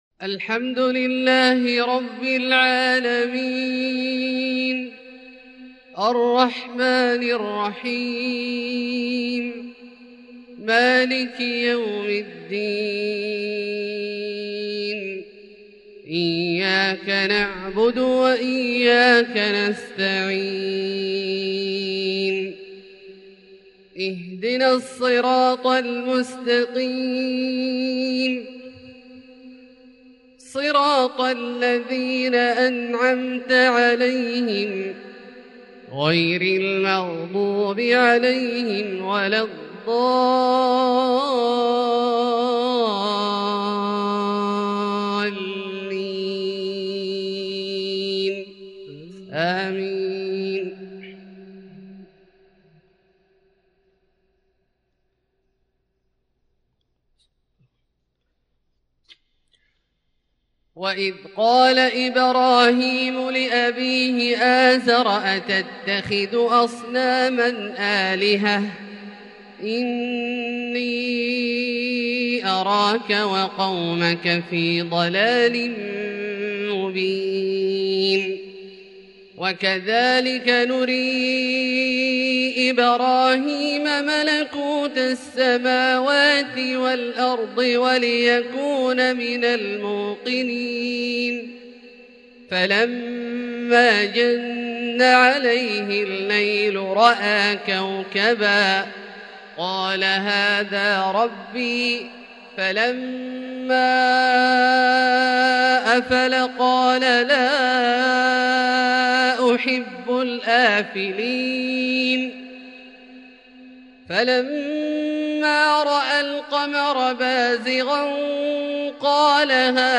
Fajr prayer from Surat Al-An'aam 18/1/2021 > H 1442 > Prayers - Abdullah Al-Juhani Recitations